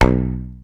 31. 31. Percussive FX 30 ZG